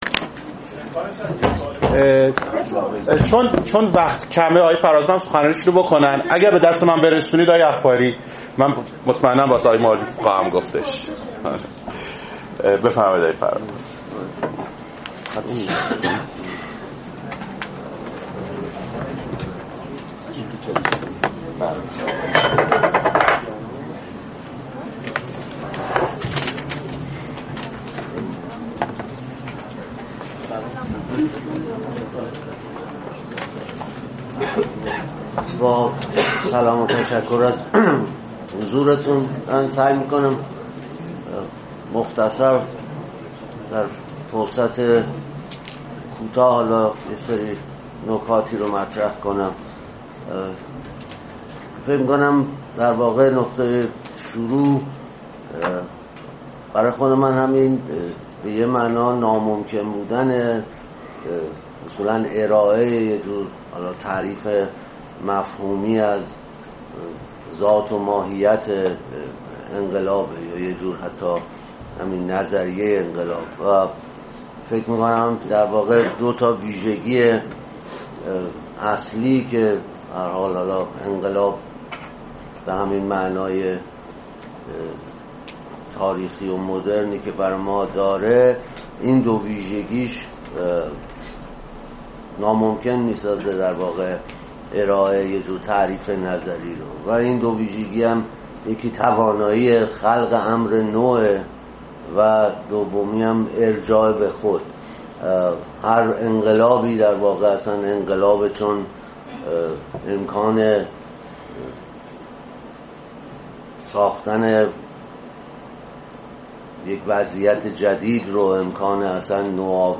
فرهنگ امروز: فایل زیر سخنرانی مراد فرهادپور در سمینار درباره انقلاب است که در تاریخ 16 اسفند در موسسه پرسش برگزار شد.